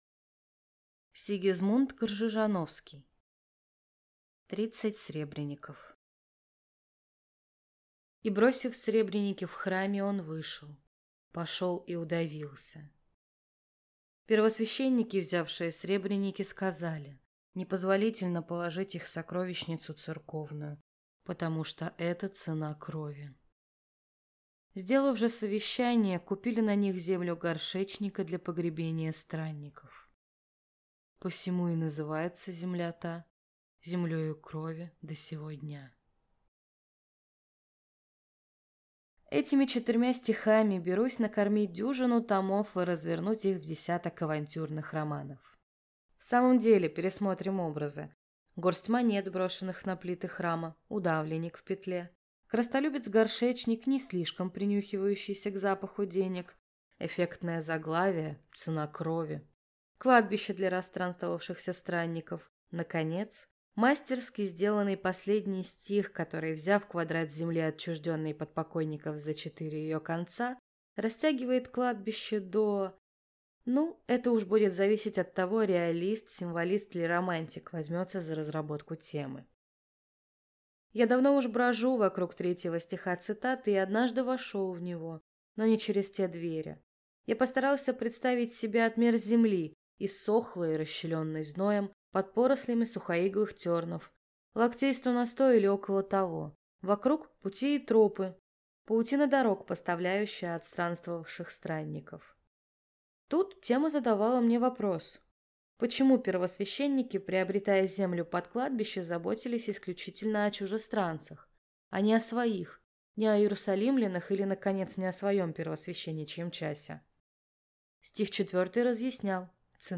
Аудиокнига Тридцать сребреников | Библиотека аудиокниг
Прослушать и бесплатно скачать фрагмент аудиокниги